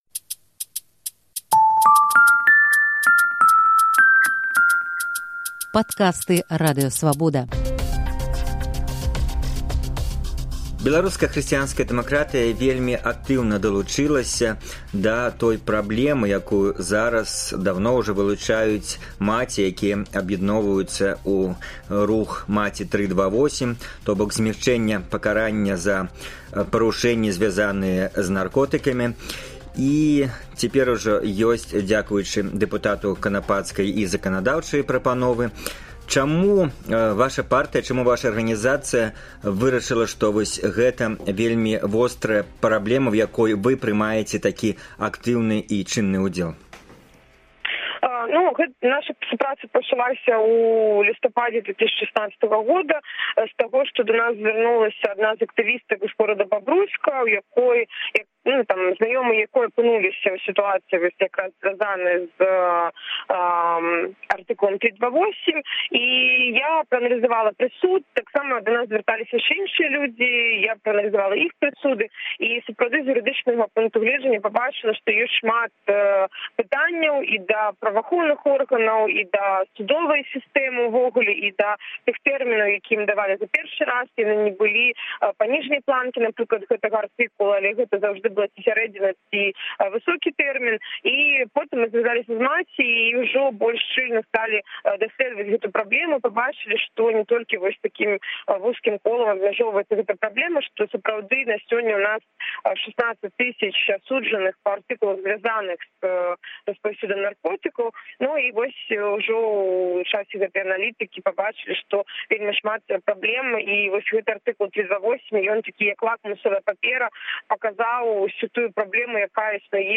Госьць Інтэрвію тыдня — сустаршыня Беларускай хрсьіянскай дэмакратыі Вольга Кавалькова. Яна тлумачыць, чаму БХД падтрымлівае «Маці 328», распавядае, чаму ўлады не гатовыя прызнаваць свае пымылкі, і адказвае на «традыцыйнае» пытаньне, ці лічыць сябе фэміністкай.